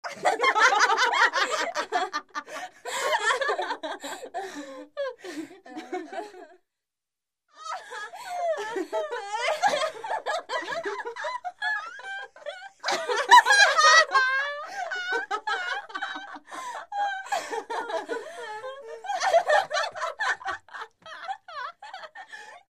Звуки женских голосов
Девушки весело смеются над шуткой